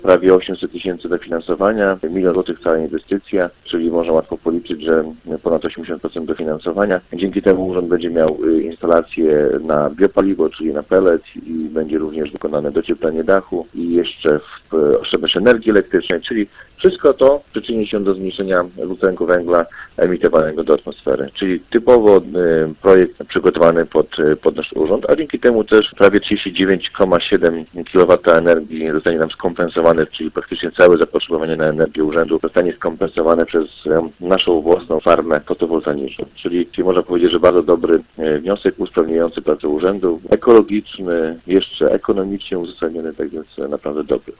– Pierwszą z inwestycji będzie przebudowa kotłowni oraz montaż instalacji paneli fotowoltaicznych w budynku Urzędu Gminy oraz Gminnego Ośrodka Kultury – mówi Radosław Król, wójt gminy Wydminy.